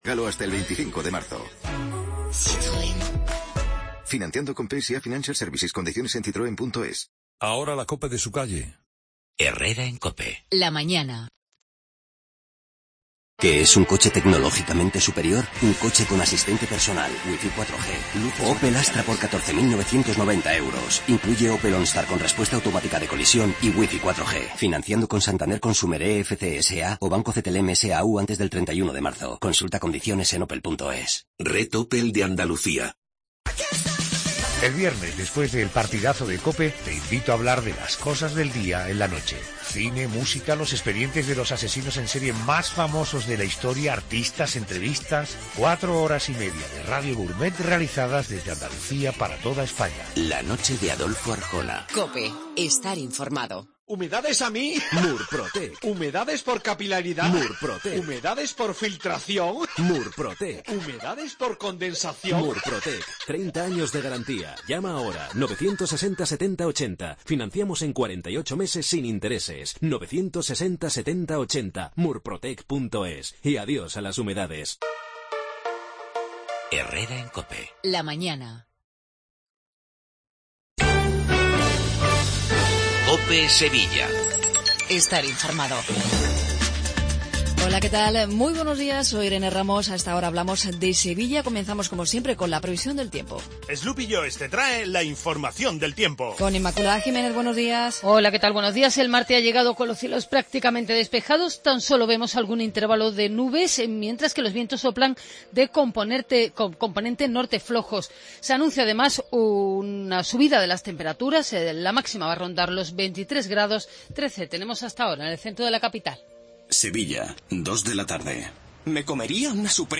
INFORMATIVO LOCAL MATINAL 8:20